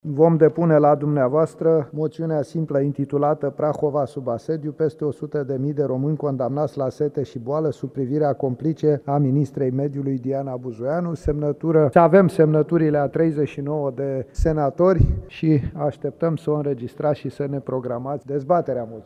Liderul senatorilor AUR, Petrişor Peiu, a anunţat, miercuri, în plenul Parlamentului depunerea unei moţiuni simple împotriva ministrei Mediului, Diana Buzoianu. Parlamentarii AUR o acuză pe ministra Mediului că ar fi știut despre procedura de golire a barajului Paltinu și despre riscurile implicate, fără să fi intervenit pentru a preveni întreruperea alimentării cu apă în județele Prahova și Dâmbovița.